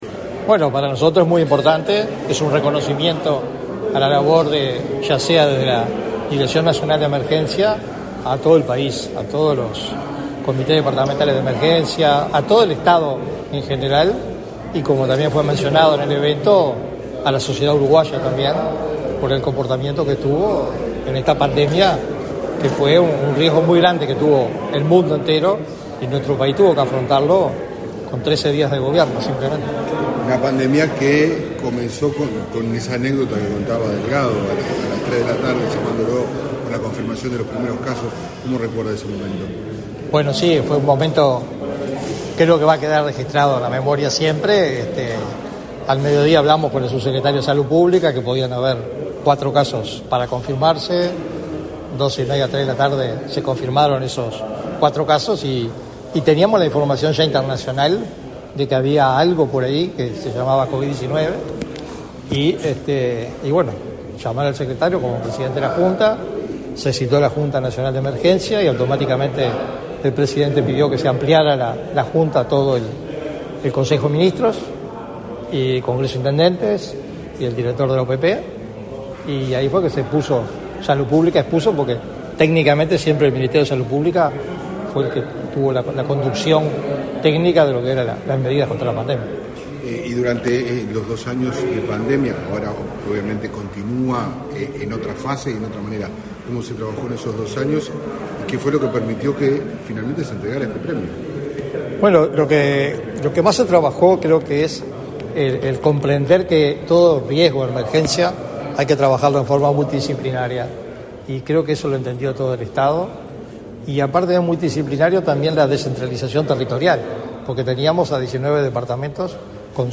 Declaraciones a la prensa del director del Sistema Nacional de Emergencias, Sergio Rico